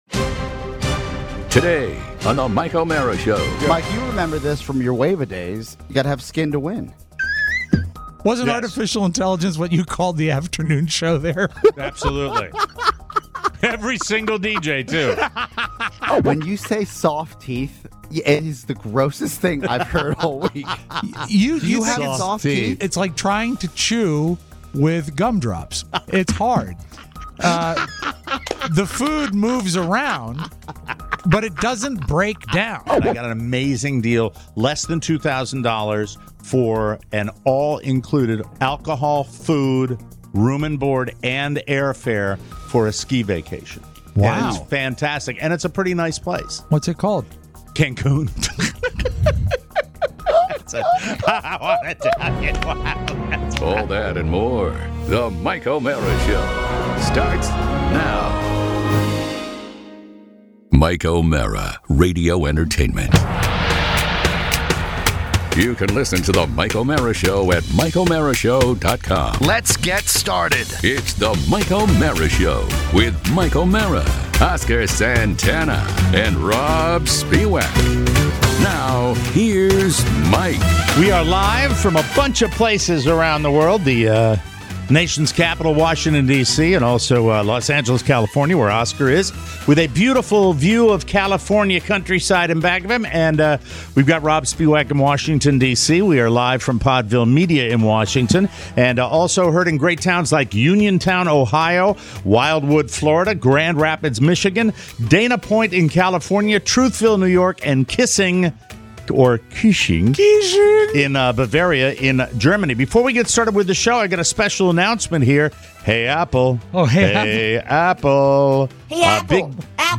broadcasting live from the left coast